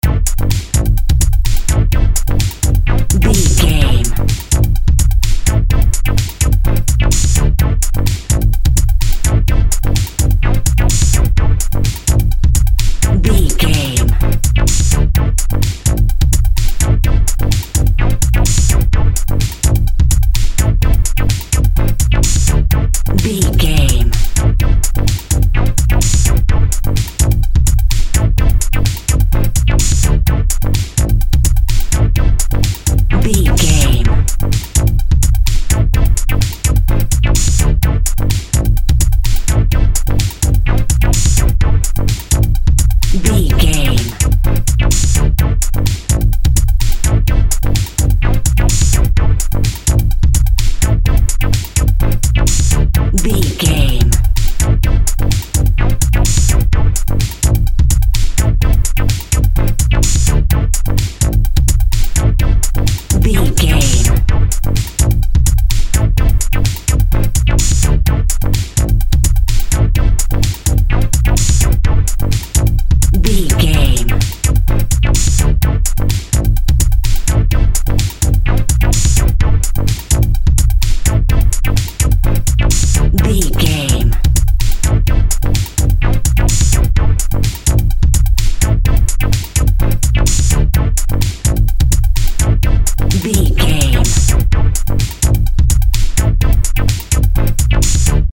Electronic Cue.
Fast paced
Aeolian/Minor
groovy
uplifting
futuristic
driving
energetic
funky
synthesiser
drum machine
Drum and bass
break beat
sub bass
synth lead
synth bass